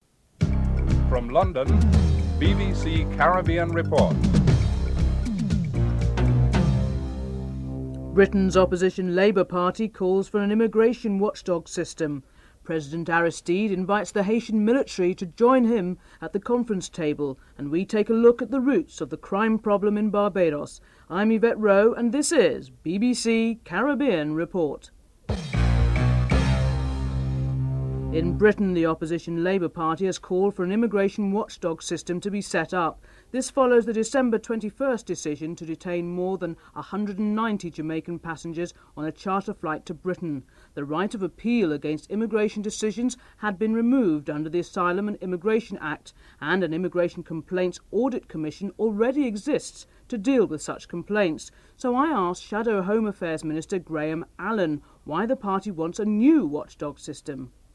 5. Interview with Former U.S. Ambassador Robert White, Centre for International Policy, discusses the structure of the conference which would include the military but the refugee status will be downgraded on the conference agenda (7:09-9:10)
8. Theme music (14:41-14:47)